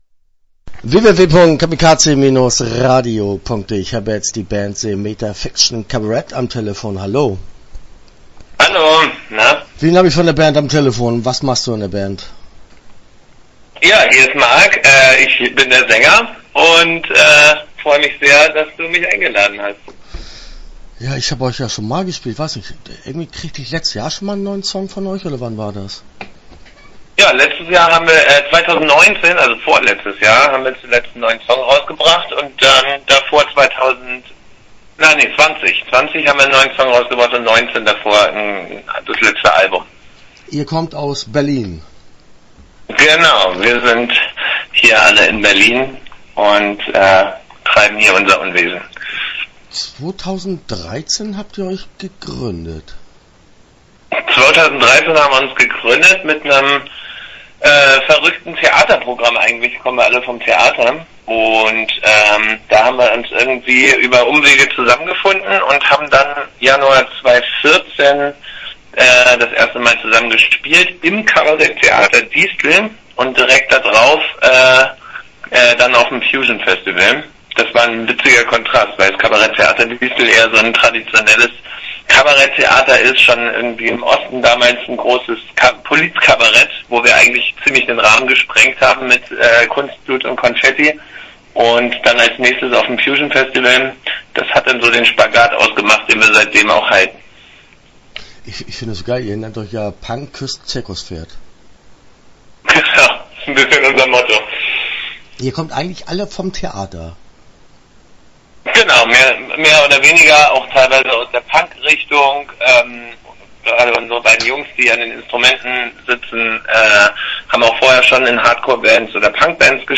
Start » Interviews » The Metafiction Cabaret